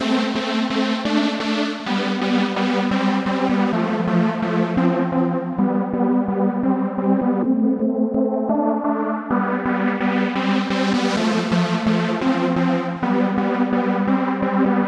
描述：一个狂欢的合成器循环
Tag: 129 bpm Rave Loops Synth Loops 2.50 MB wav Key : Unknown